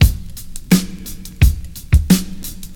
Free drum groove - kick tuned to the C note. Loudest frequency: 1381Hz
86-bpm-drum-beat-c-key-3Jw.wav